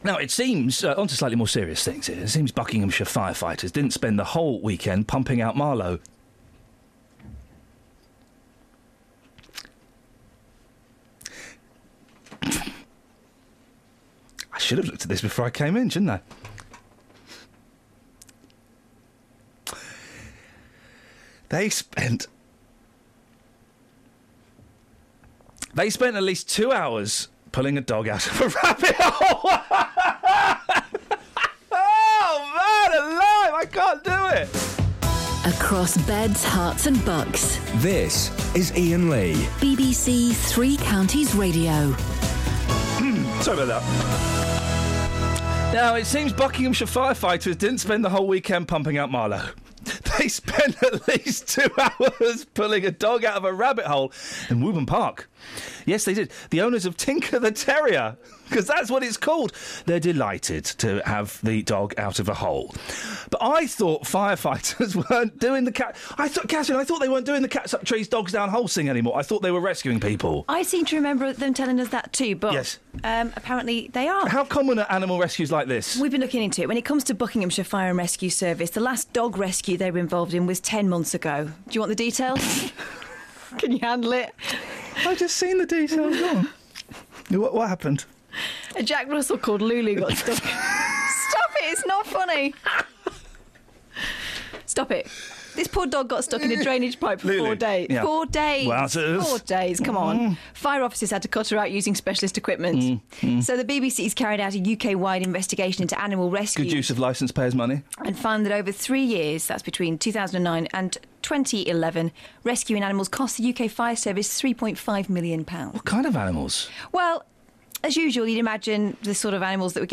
Shouldn't laugh but... they did.